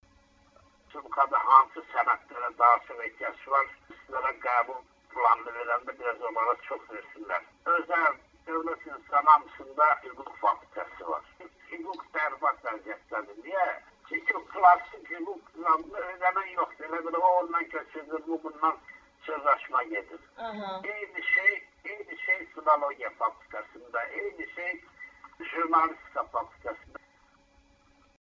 SORĞU: Gənclərin çox, sən demə, istəmədiyi yerdə çalışır